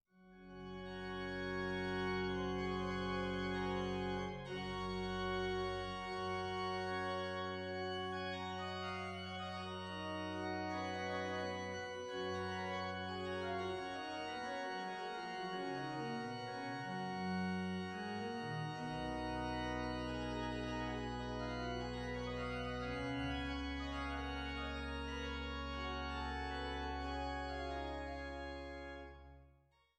Hildebrandt-Orgel in Langhennersdorf